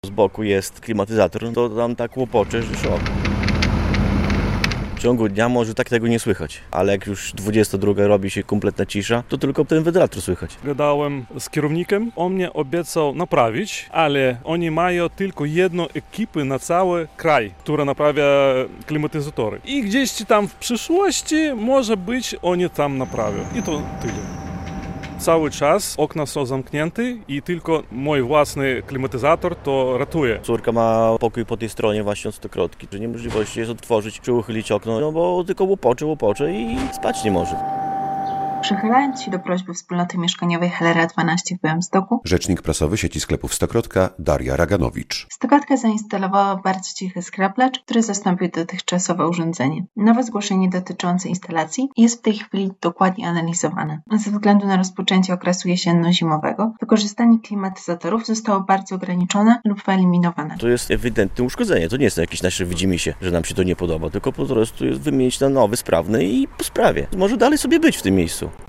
Mieszkańcy białostockiego bloku narzekają na głośną klimatyzację w jednym z pobliskich sklepów - relacja